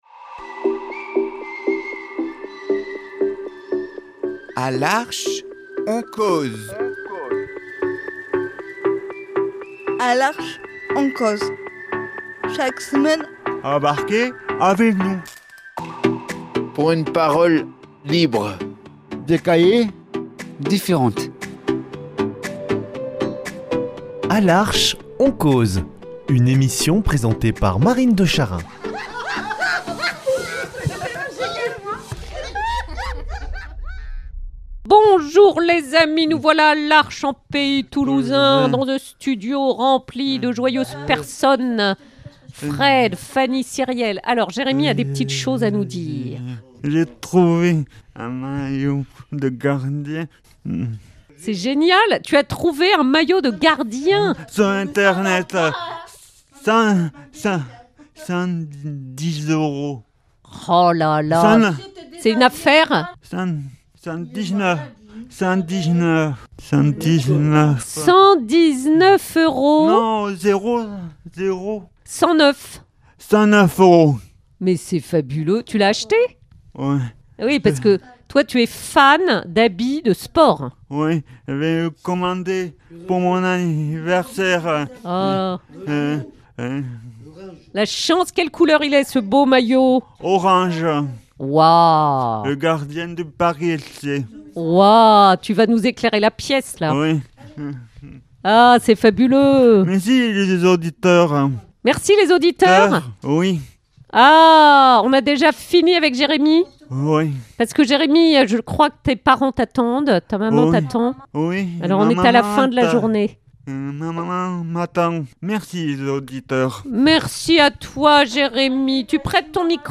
Le micro libère de 1001 manières la parole de nos Amis, discrète, audacieuse, fluide, passionnée, toujours vraie, joyeuse ou grave.